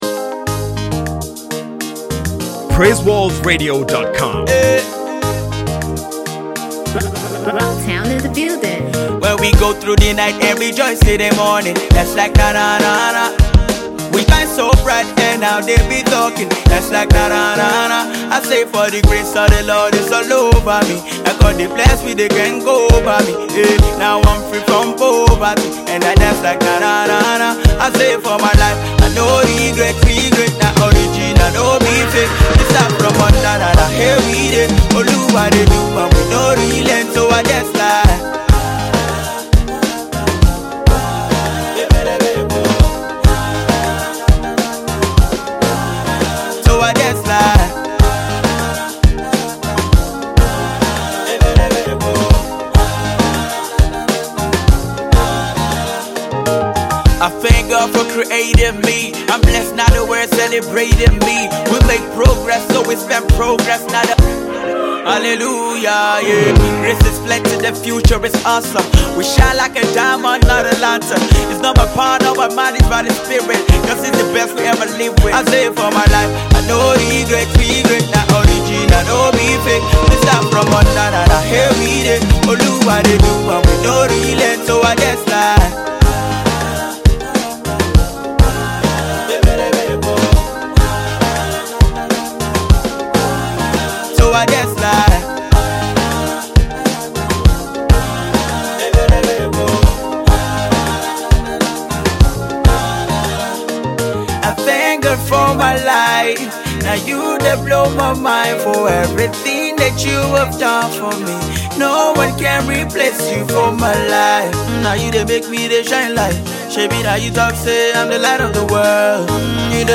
a feel good tune